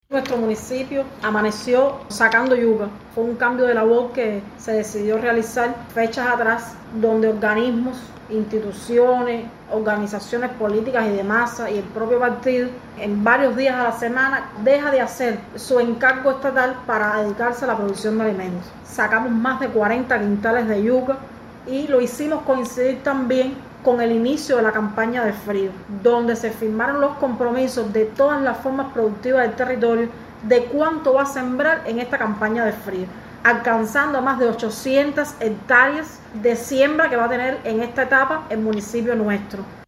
Así lo comentó Hanny Martínez Martínez, primera secretaria del Partido en la localidad.